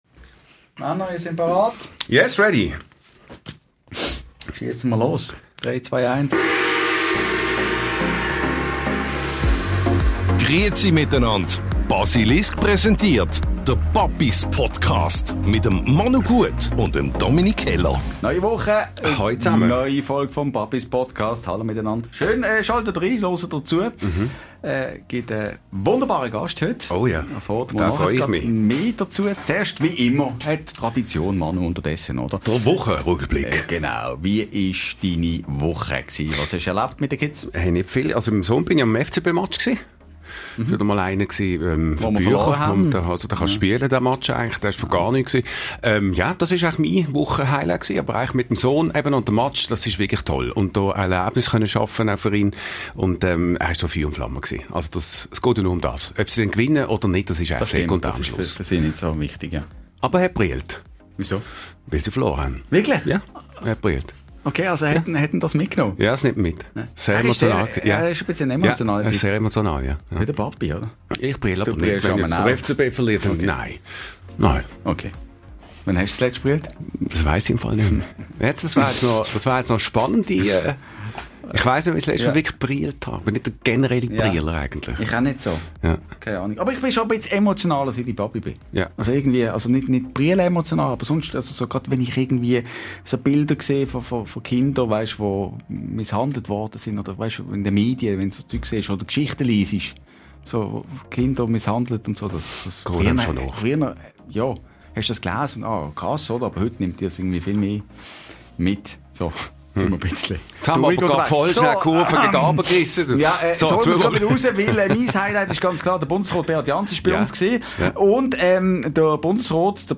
Im Staffelfinale begrüssen unsere Papis Marc Trauffer!
Zum Schluss verrät uns Trauffer, was seine Kinder auf Weihnachten bekommen und die Papis singen eine Runde «Last Christmas» vor dem Kaminfeuer.